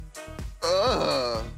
UGGGHHHHHhhhhhh
uggghhhhhhhhhhh.mp3